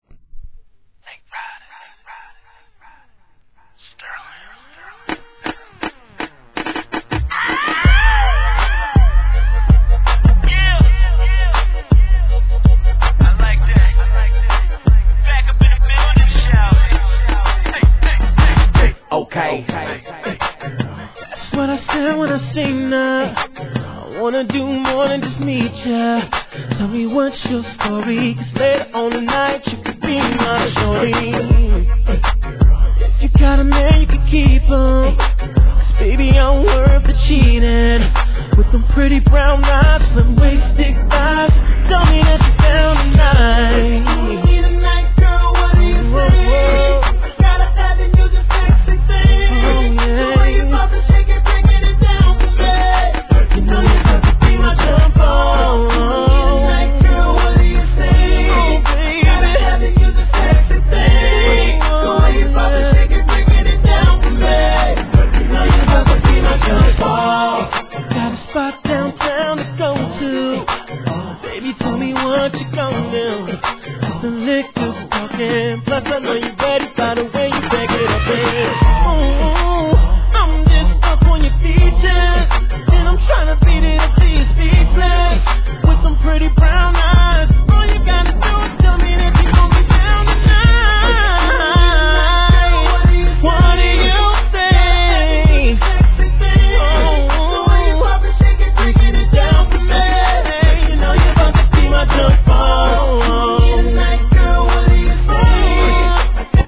HIP HOP/R&B
シンセの効いたミッドテンポでスキルある歌声を効かせます!!